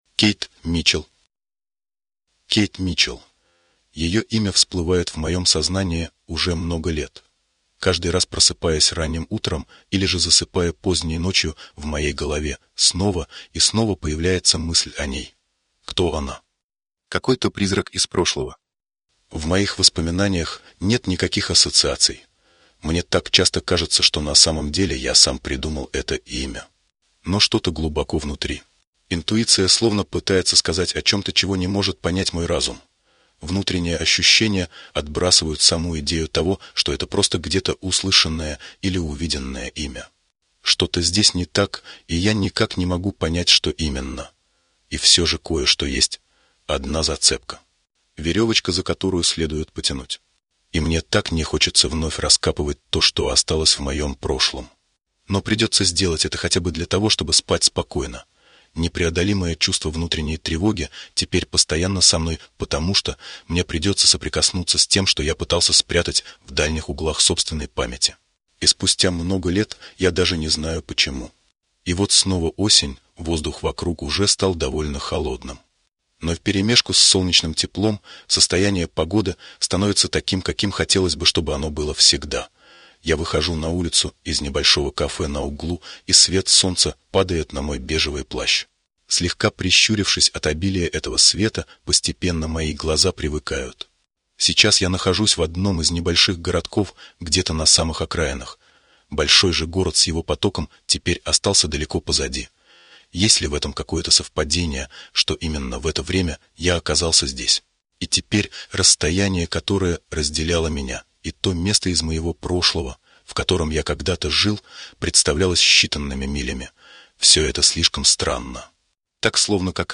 Аудиокнига Кейт Митчелл. Рассказ | Библиотека аудиокниг